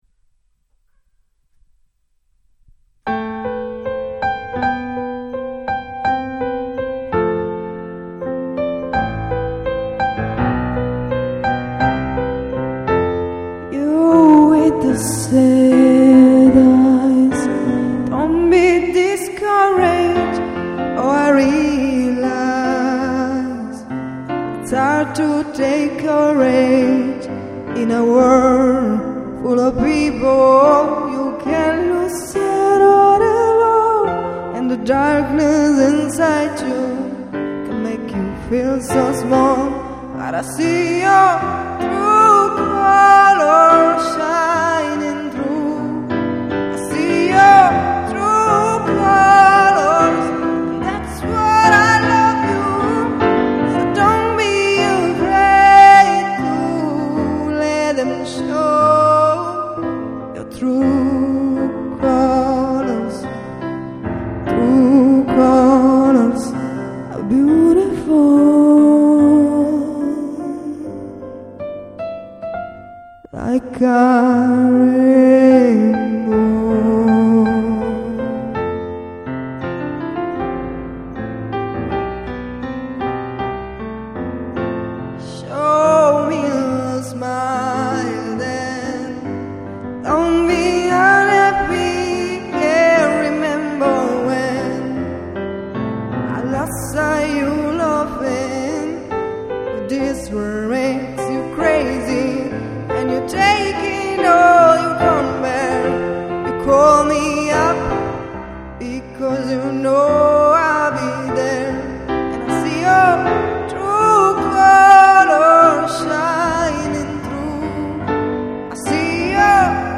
Voce e Pianoforte
cover